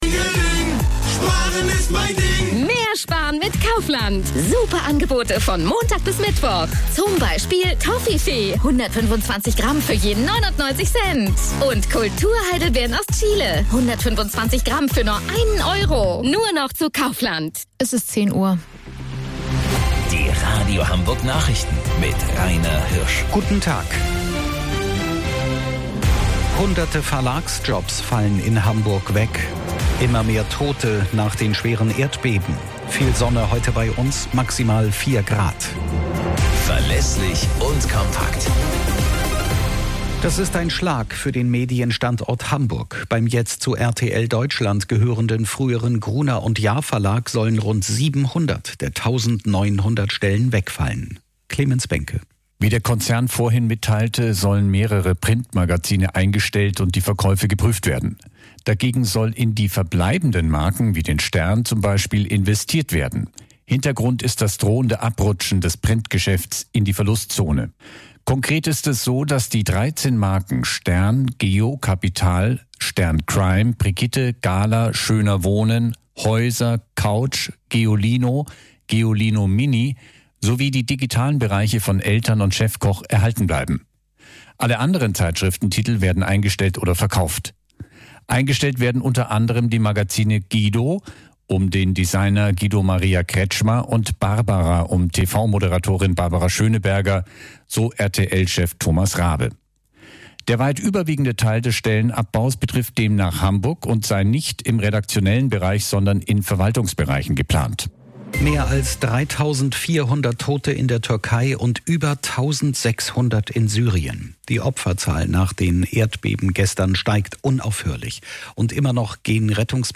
Radio Hamburg Nachrichten vom 12.06.2022 um 09 Uhr - 12.06.2022